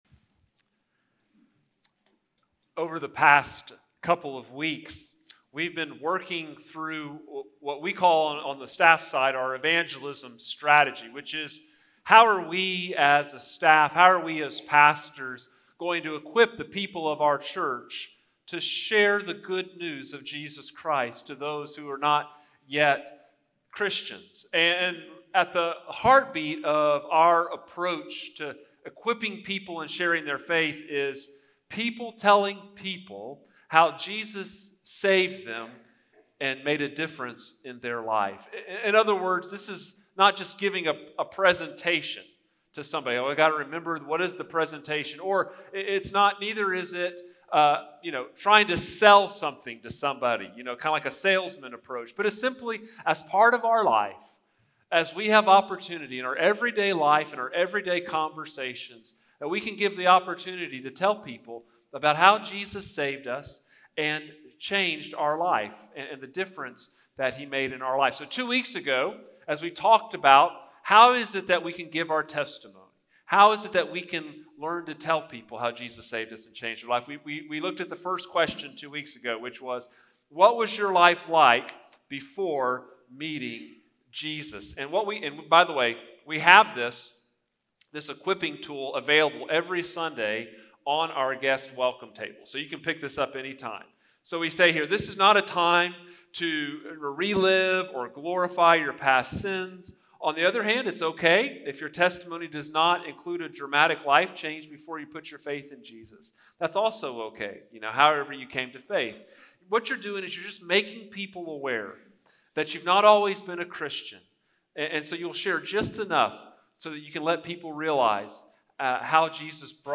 Sermons | First Baptist Church, Brenham, Texas